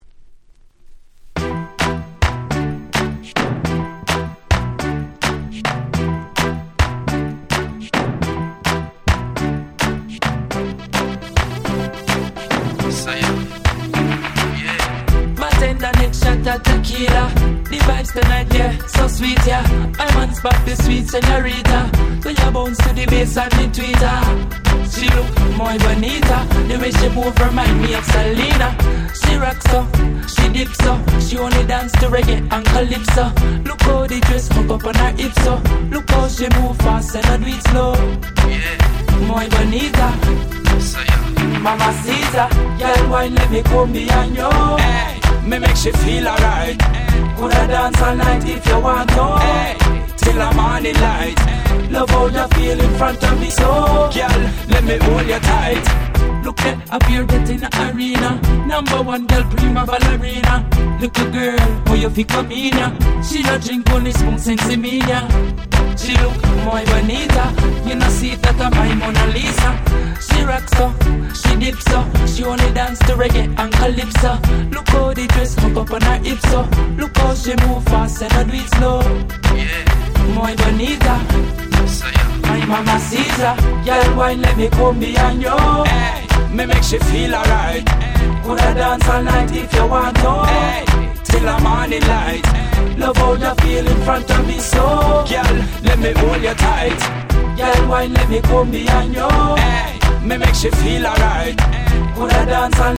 07' Smash Hit Reggae !!
ラテン風味の格好良いDancehallナンバーです！
レゲエ ダンスホール ラテン